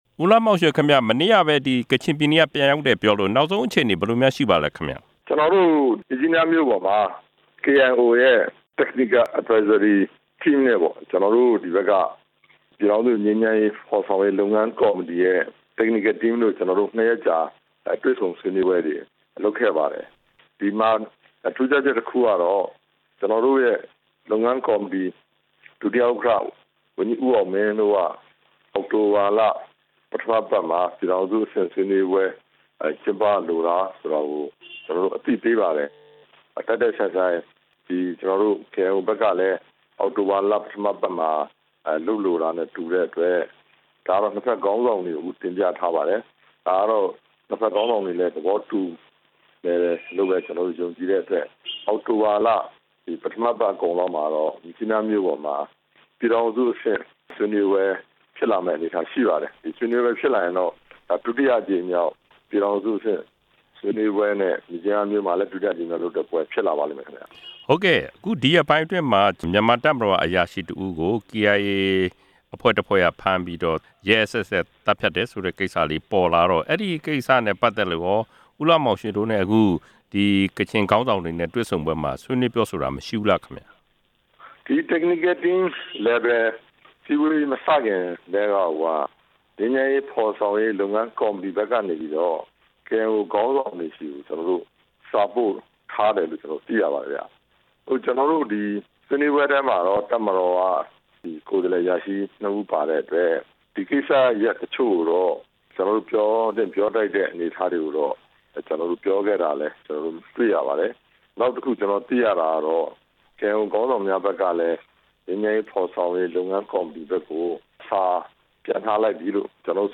မြန်မာစစ်ဗိုလ် သတ်ဖြတ်ခံရမှု ငြိမ်းချမ်းရေး အကျိုးတော်ဆောင်နဲ့ ဆက်သွယ်မေးမြန်းချက်